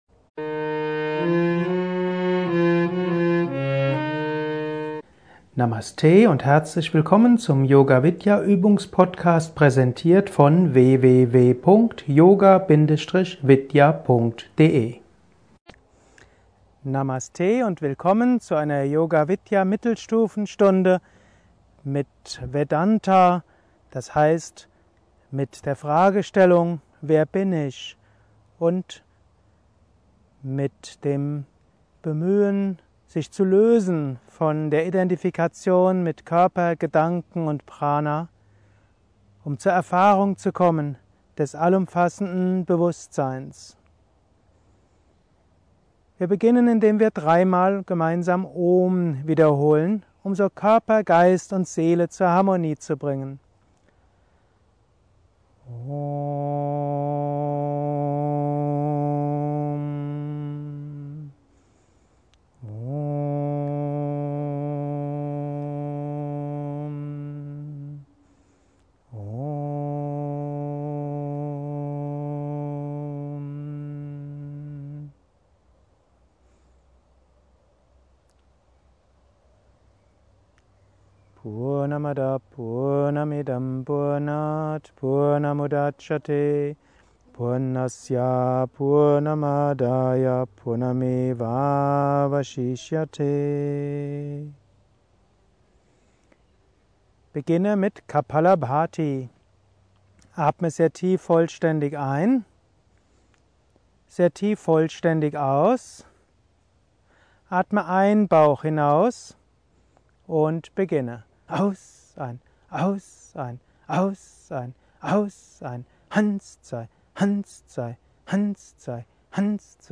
166_Yogastunde_Mittelstufe_Jnana_Yoga_44_Minuten.mp3